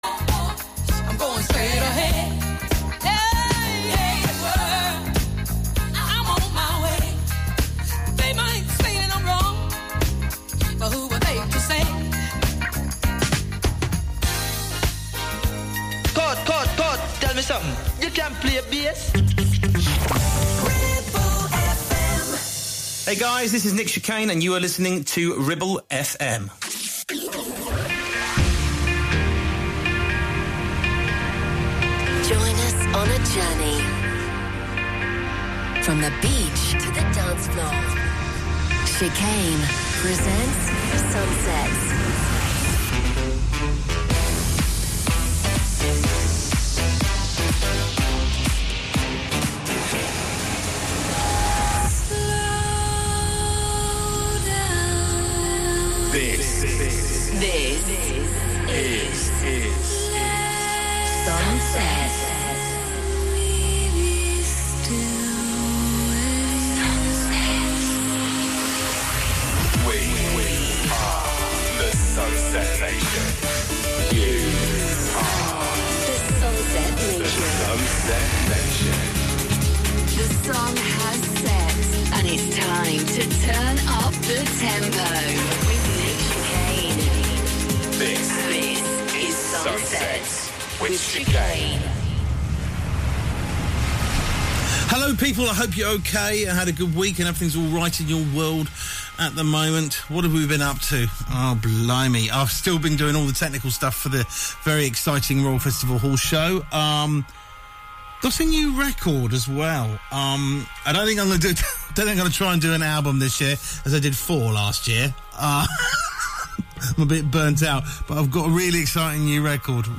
Ribble FM Sun Sets A wonderful way to start your weekend, chilled start and raising the tempo.